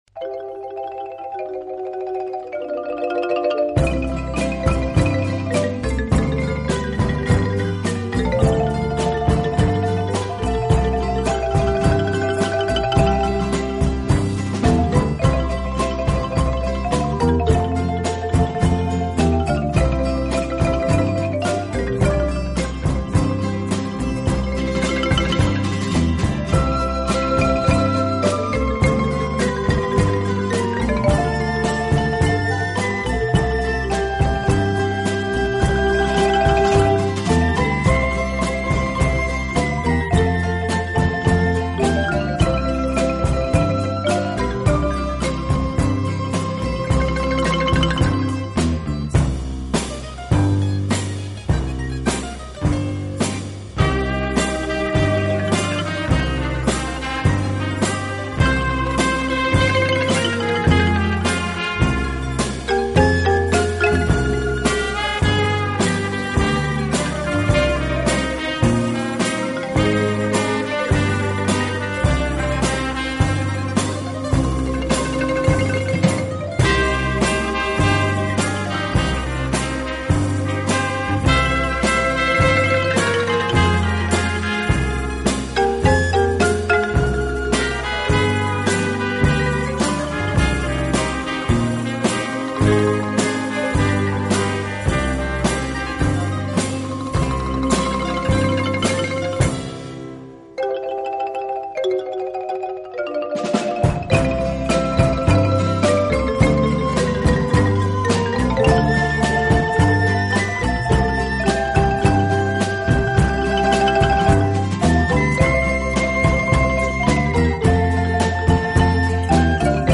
以擅长演奏拉丁美洲音乐而著称。
乐器的演奏，具有拉美音乐独特的韵味。